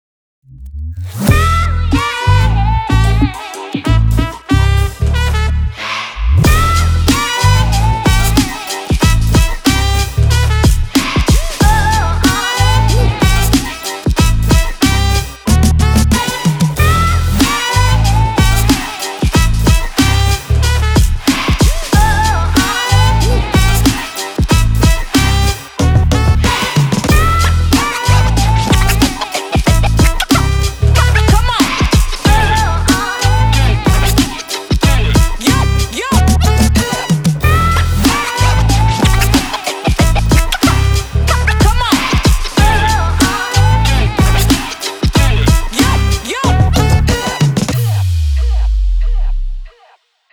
Hip-Hop Intro.wav